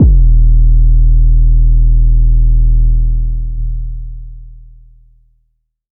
• phonk kicks oneshot 1 - 808 E.wav
Specially designed for phonk type beats, these nasty, layered 808 one shots are just what you need, can also help designing Hip Hop, Trap, Pop, Future Bass or EDM. Enjoy these fat, disrespectful 808 ...
phonk_kicks_oneshot_1_-_808_E_L7P.wav